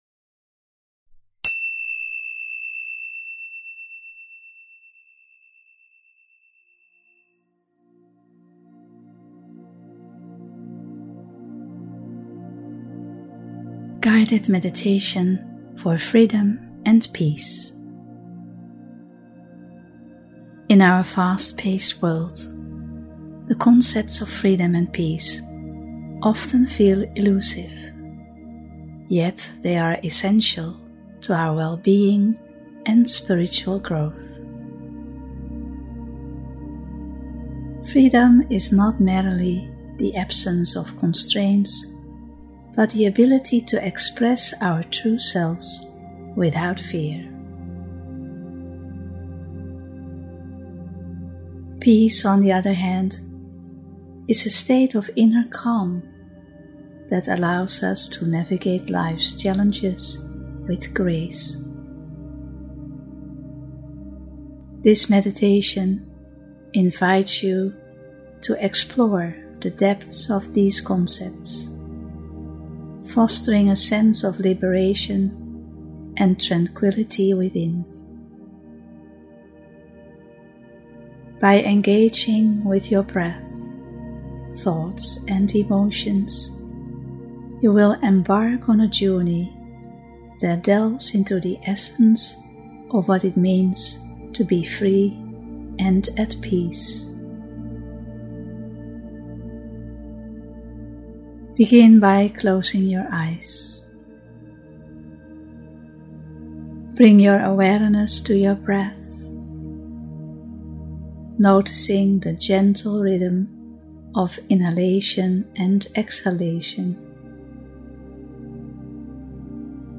Meditation“Guided Meditation forFreedom and Peace”
220-guided-meditation-for-freedom-and-peace.mp3